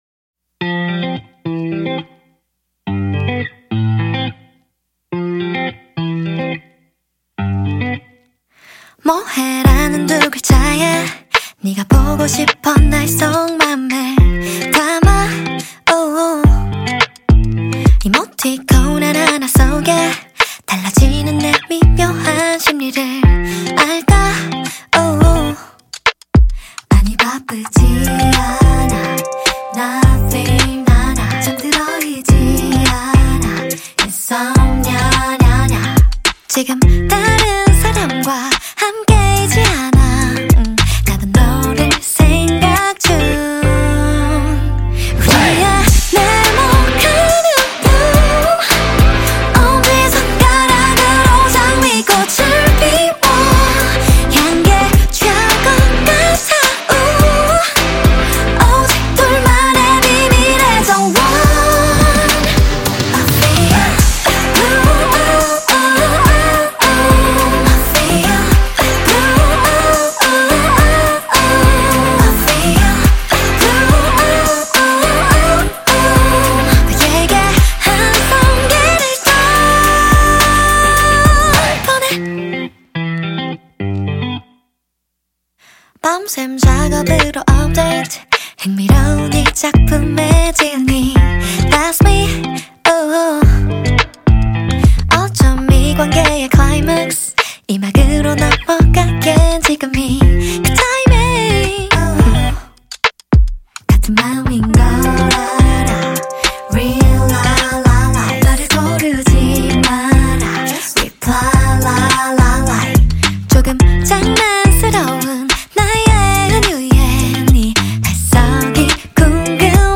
کی پاپ Kpop آهنگ کره ای